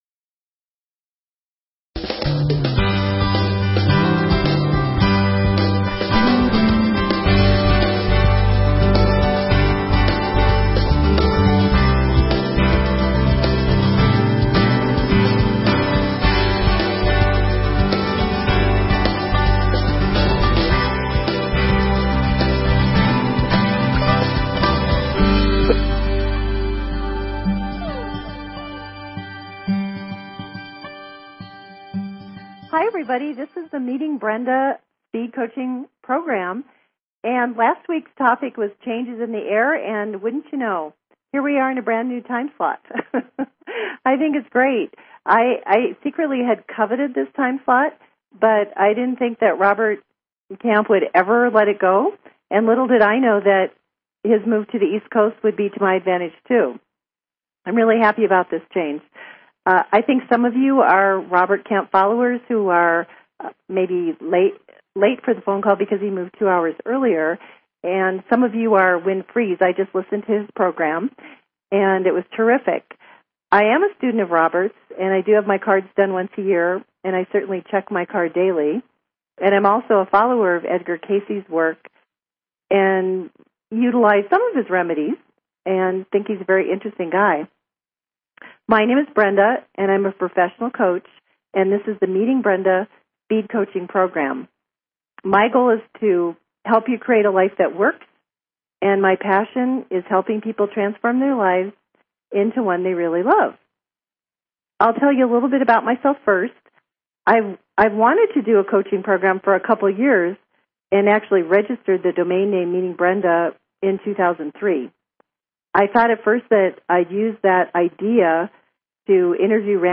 Talk Show Episode
Callers want ideas on how they can shift their energy into a better feeling state of mind.